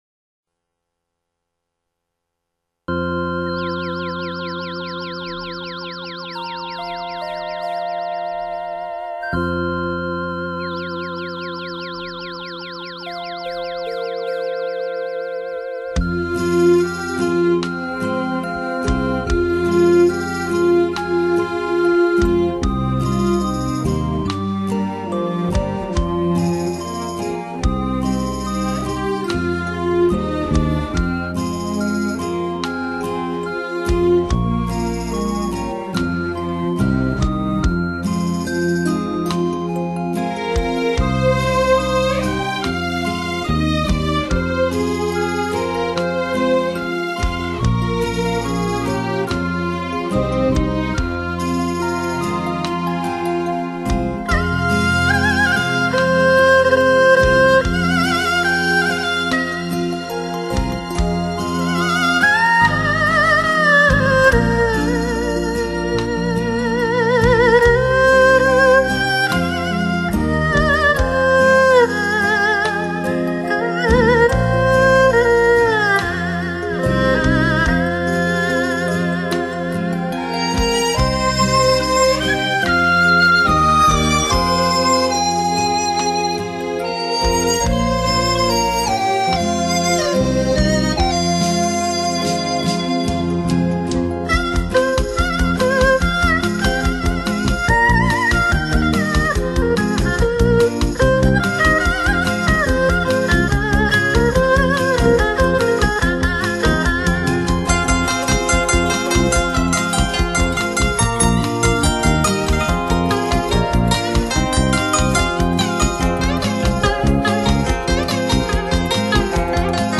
二胡是我国优秀民族传统乐器中最有特色的乐器
它的音色深厚、甜润、纯正而雅致，能给人以悦耳动听
刚劲而秀美之感，接近人声又略带伤惋的艺术魄力